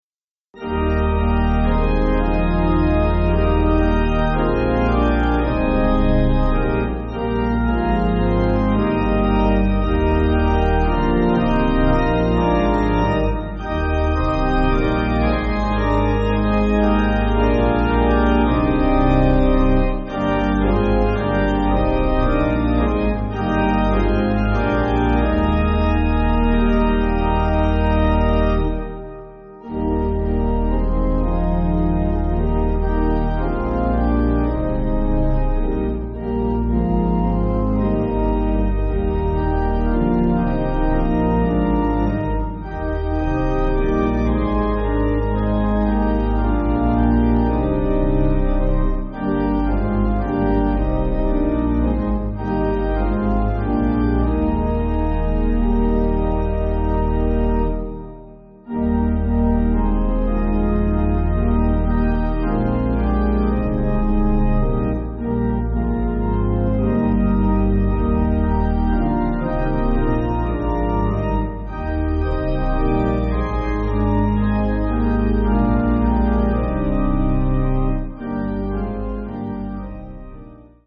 (CM)   5/Eb